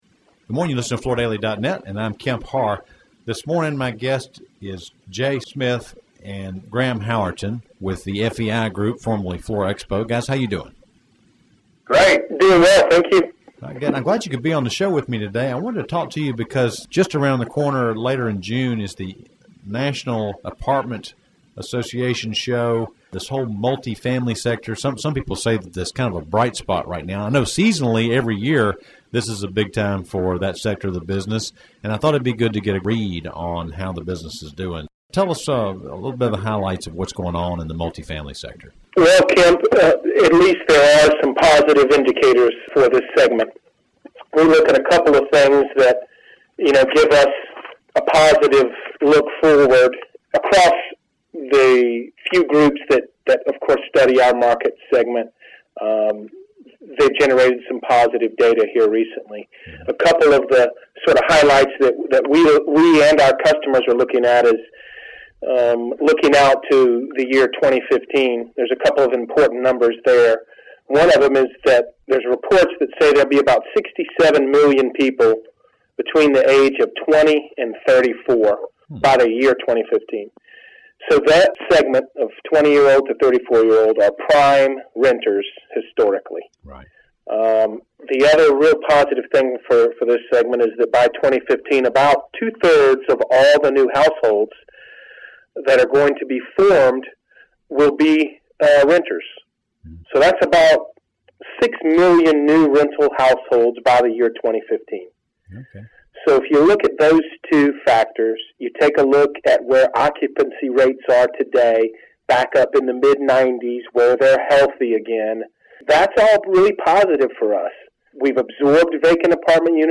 Listen to the interview to hear current and future statistics about occupancy, growth, and changes in the types of flooring used in the rental markets.